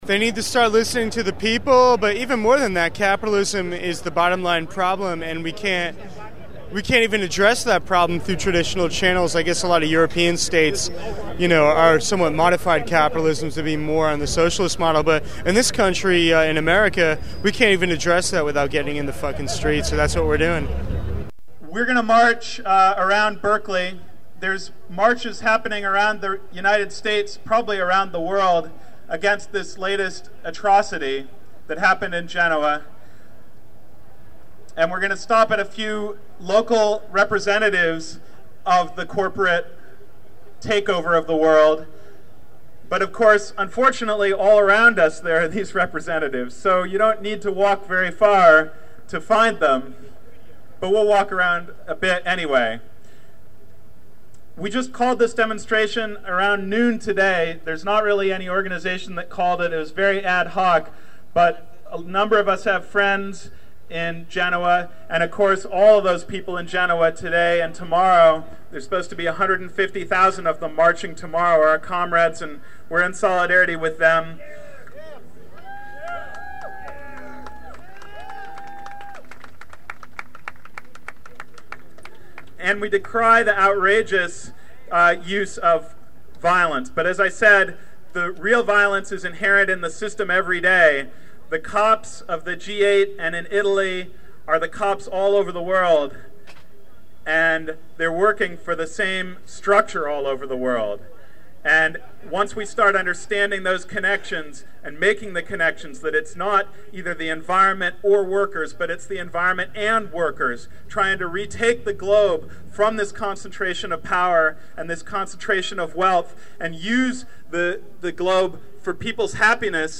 Speeches and beginning of march in Berkeley 7/20/01 in solidarity with protesters being beaten, gassed, and killed in Genoa -- 3 min.
About 200 people rallied at the Berkeley BART station Saturday evening to mourn and protest the shooting death that morning of 23-year-old Carlo Giuliani in Genoa during the G8 riots.
Here is a collage of sound from the rally & march: